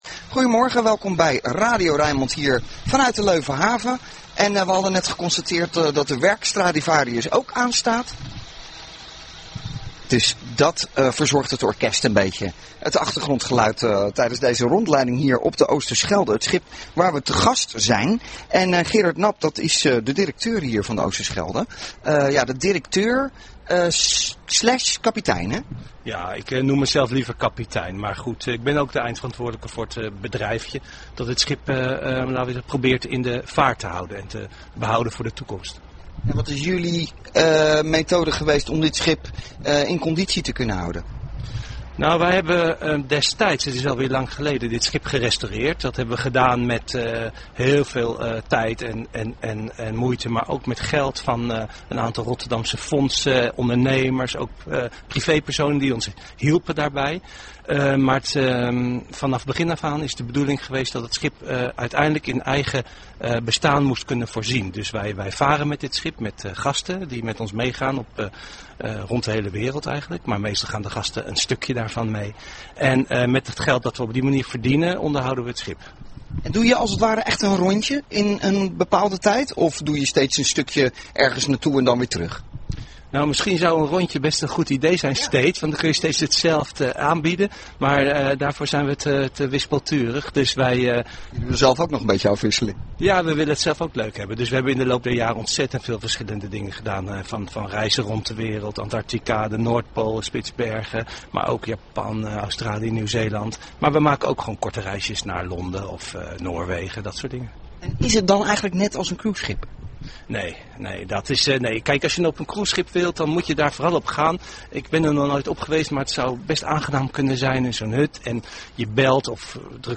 Radio Rijnmond aan boord van de OOSTERSCHELDE.